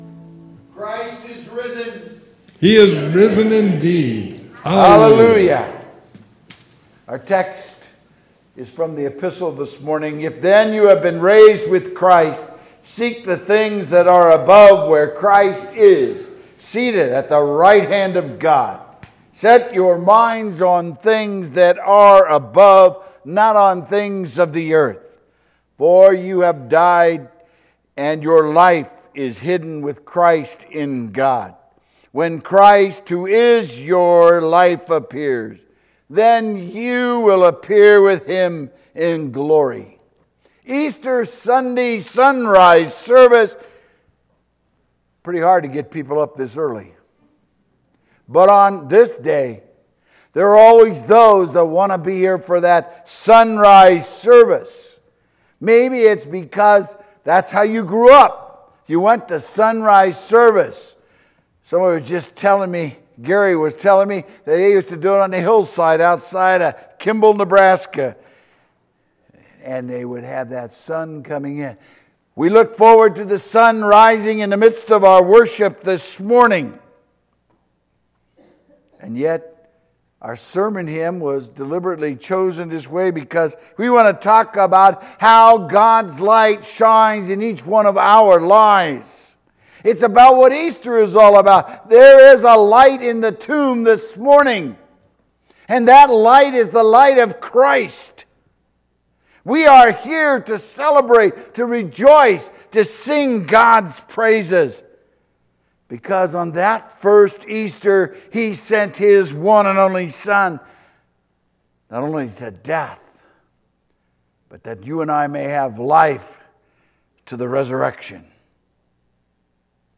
Sermon Easter SonRise Service April 9, 2023